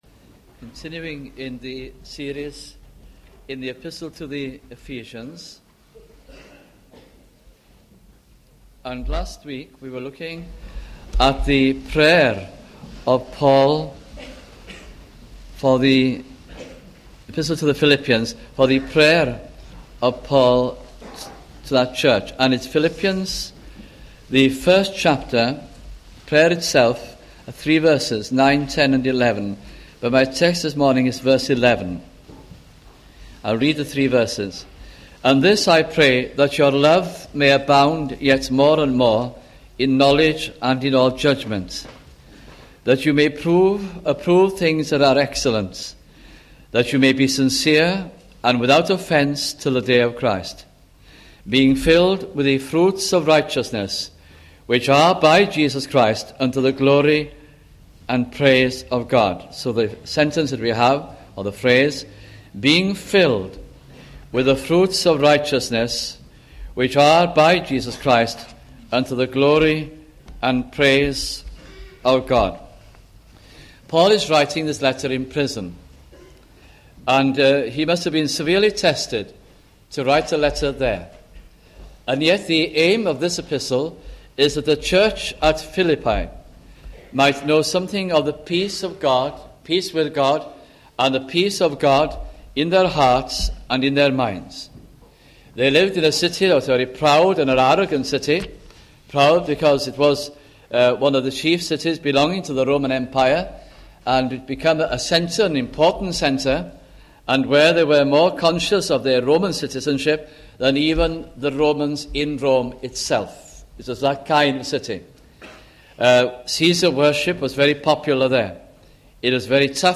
» Philippians Series 1989-90 » sunday morning messages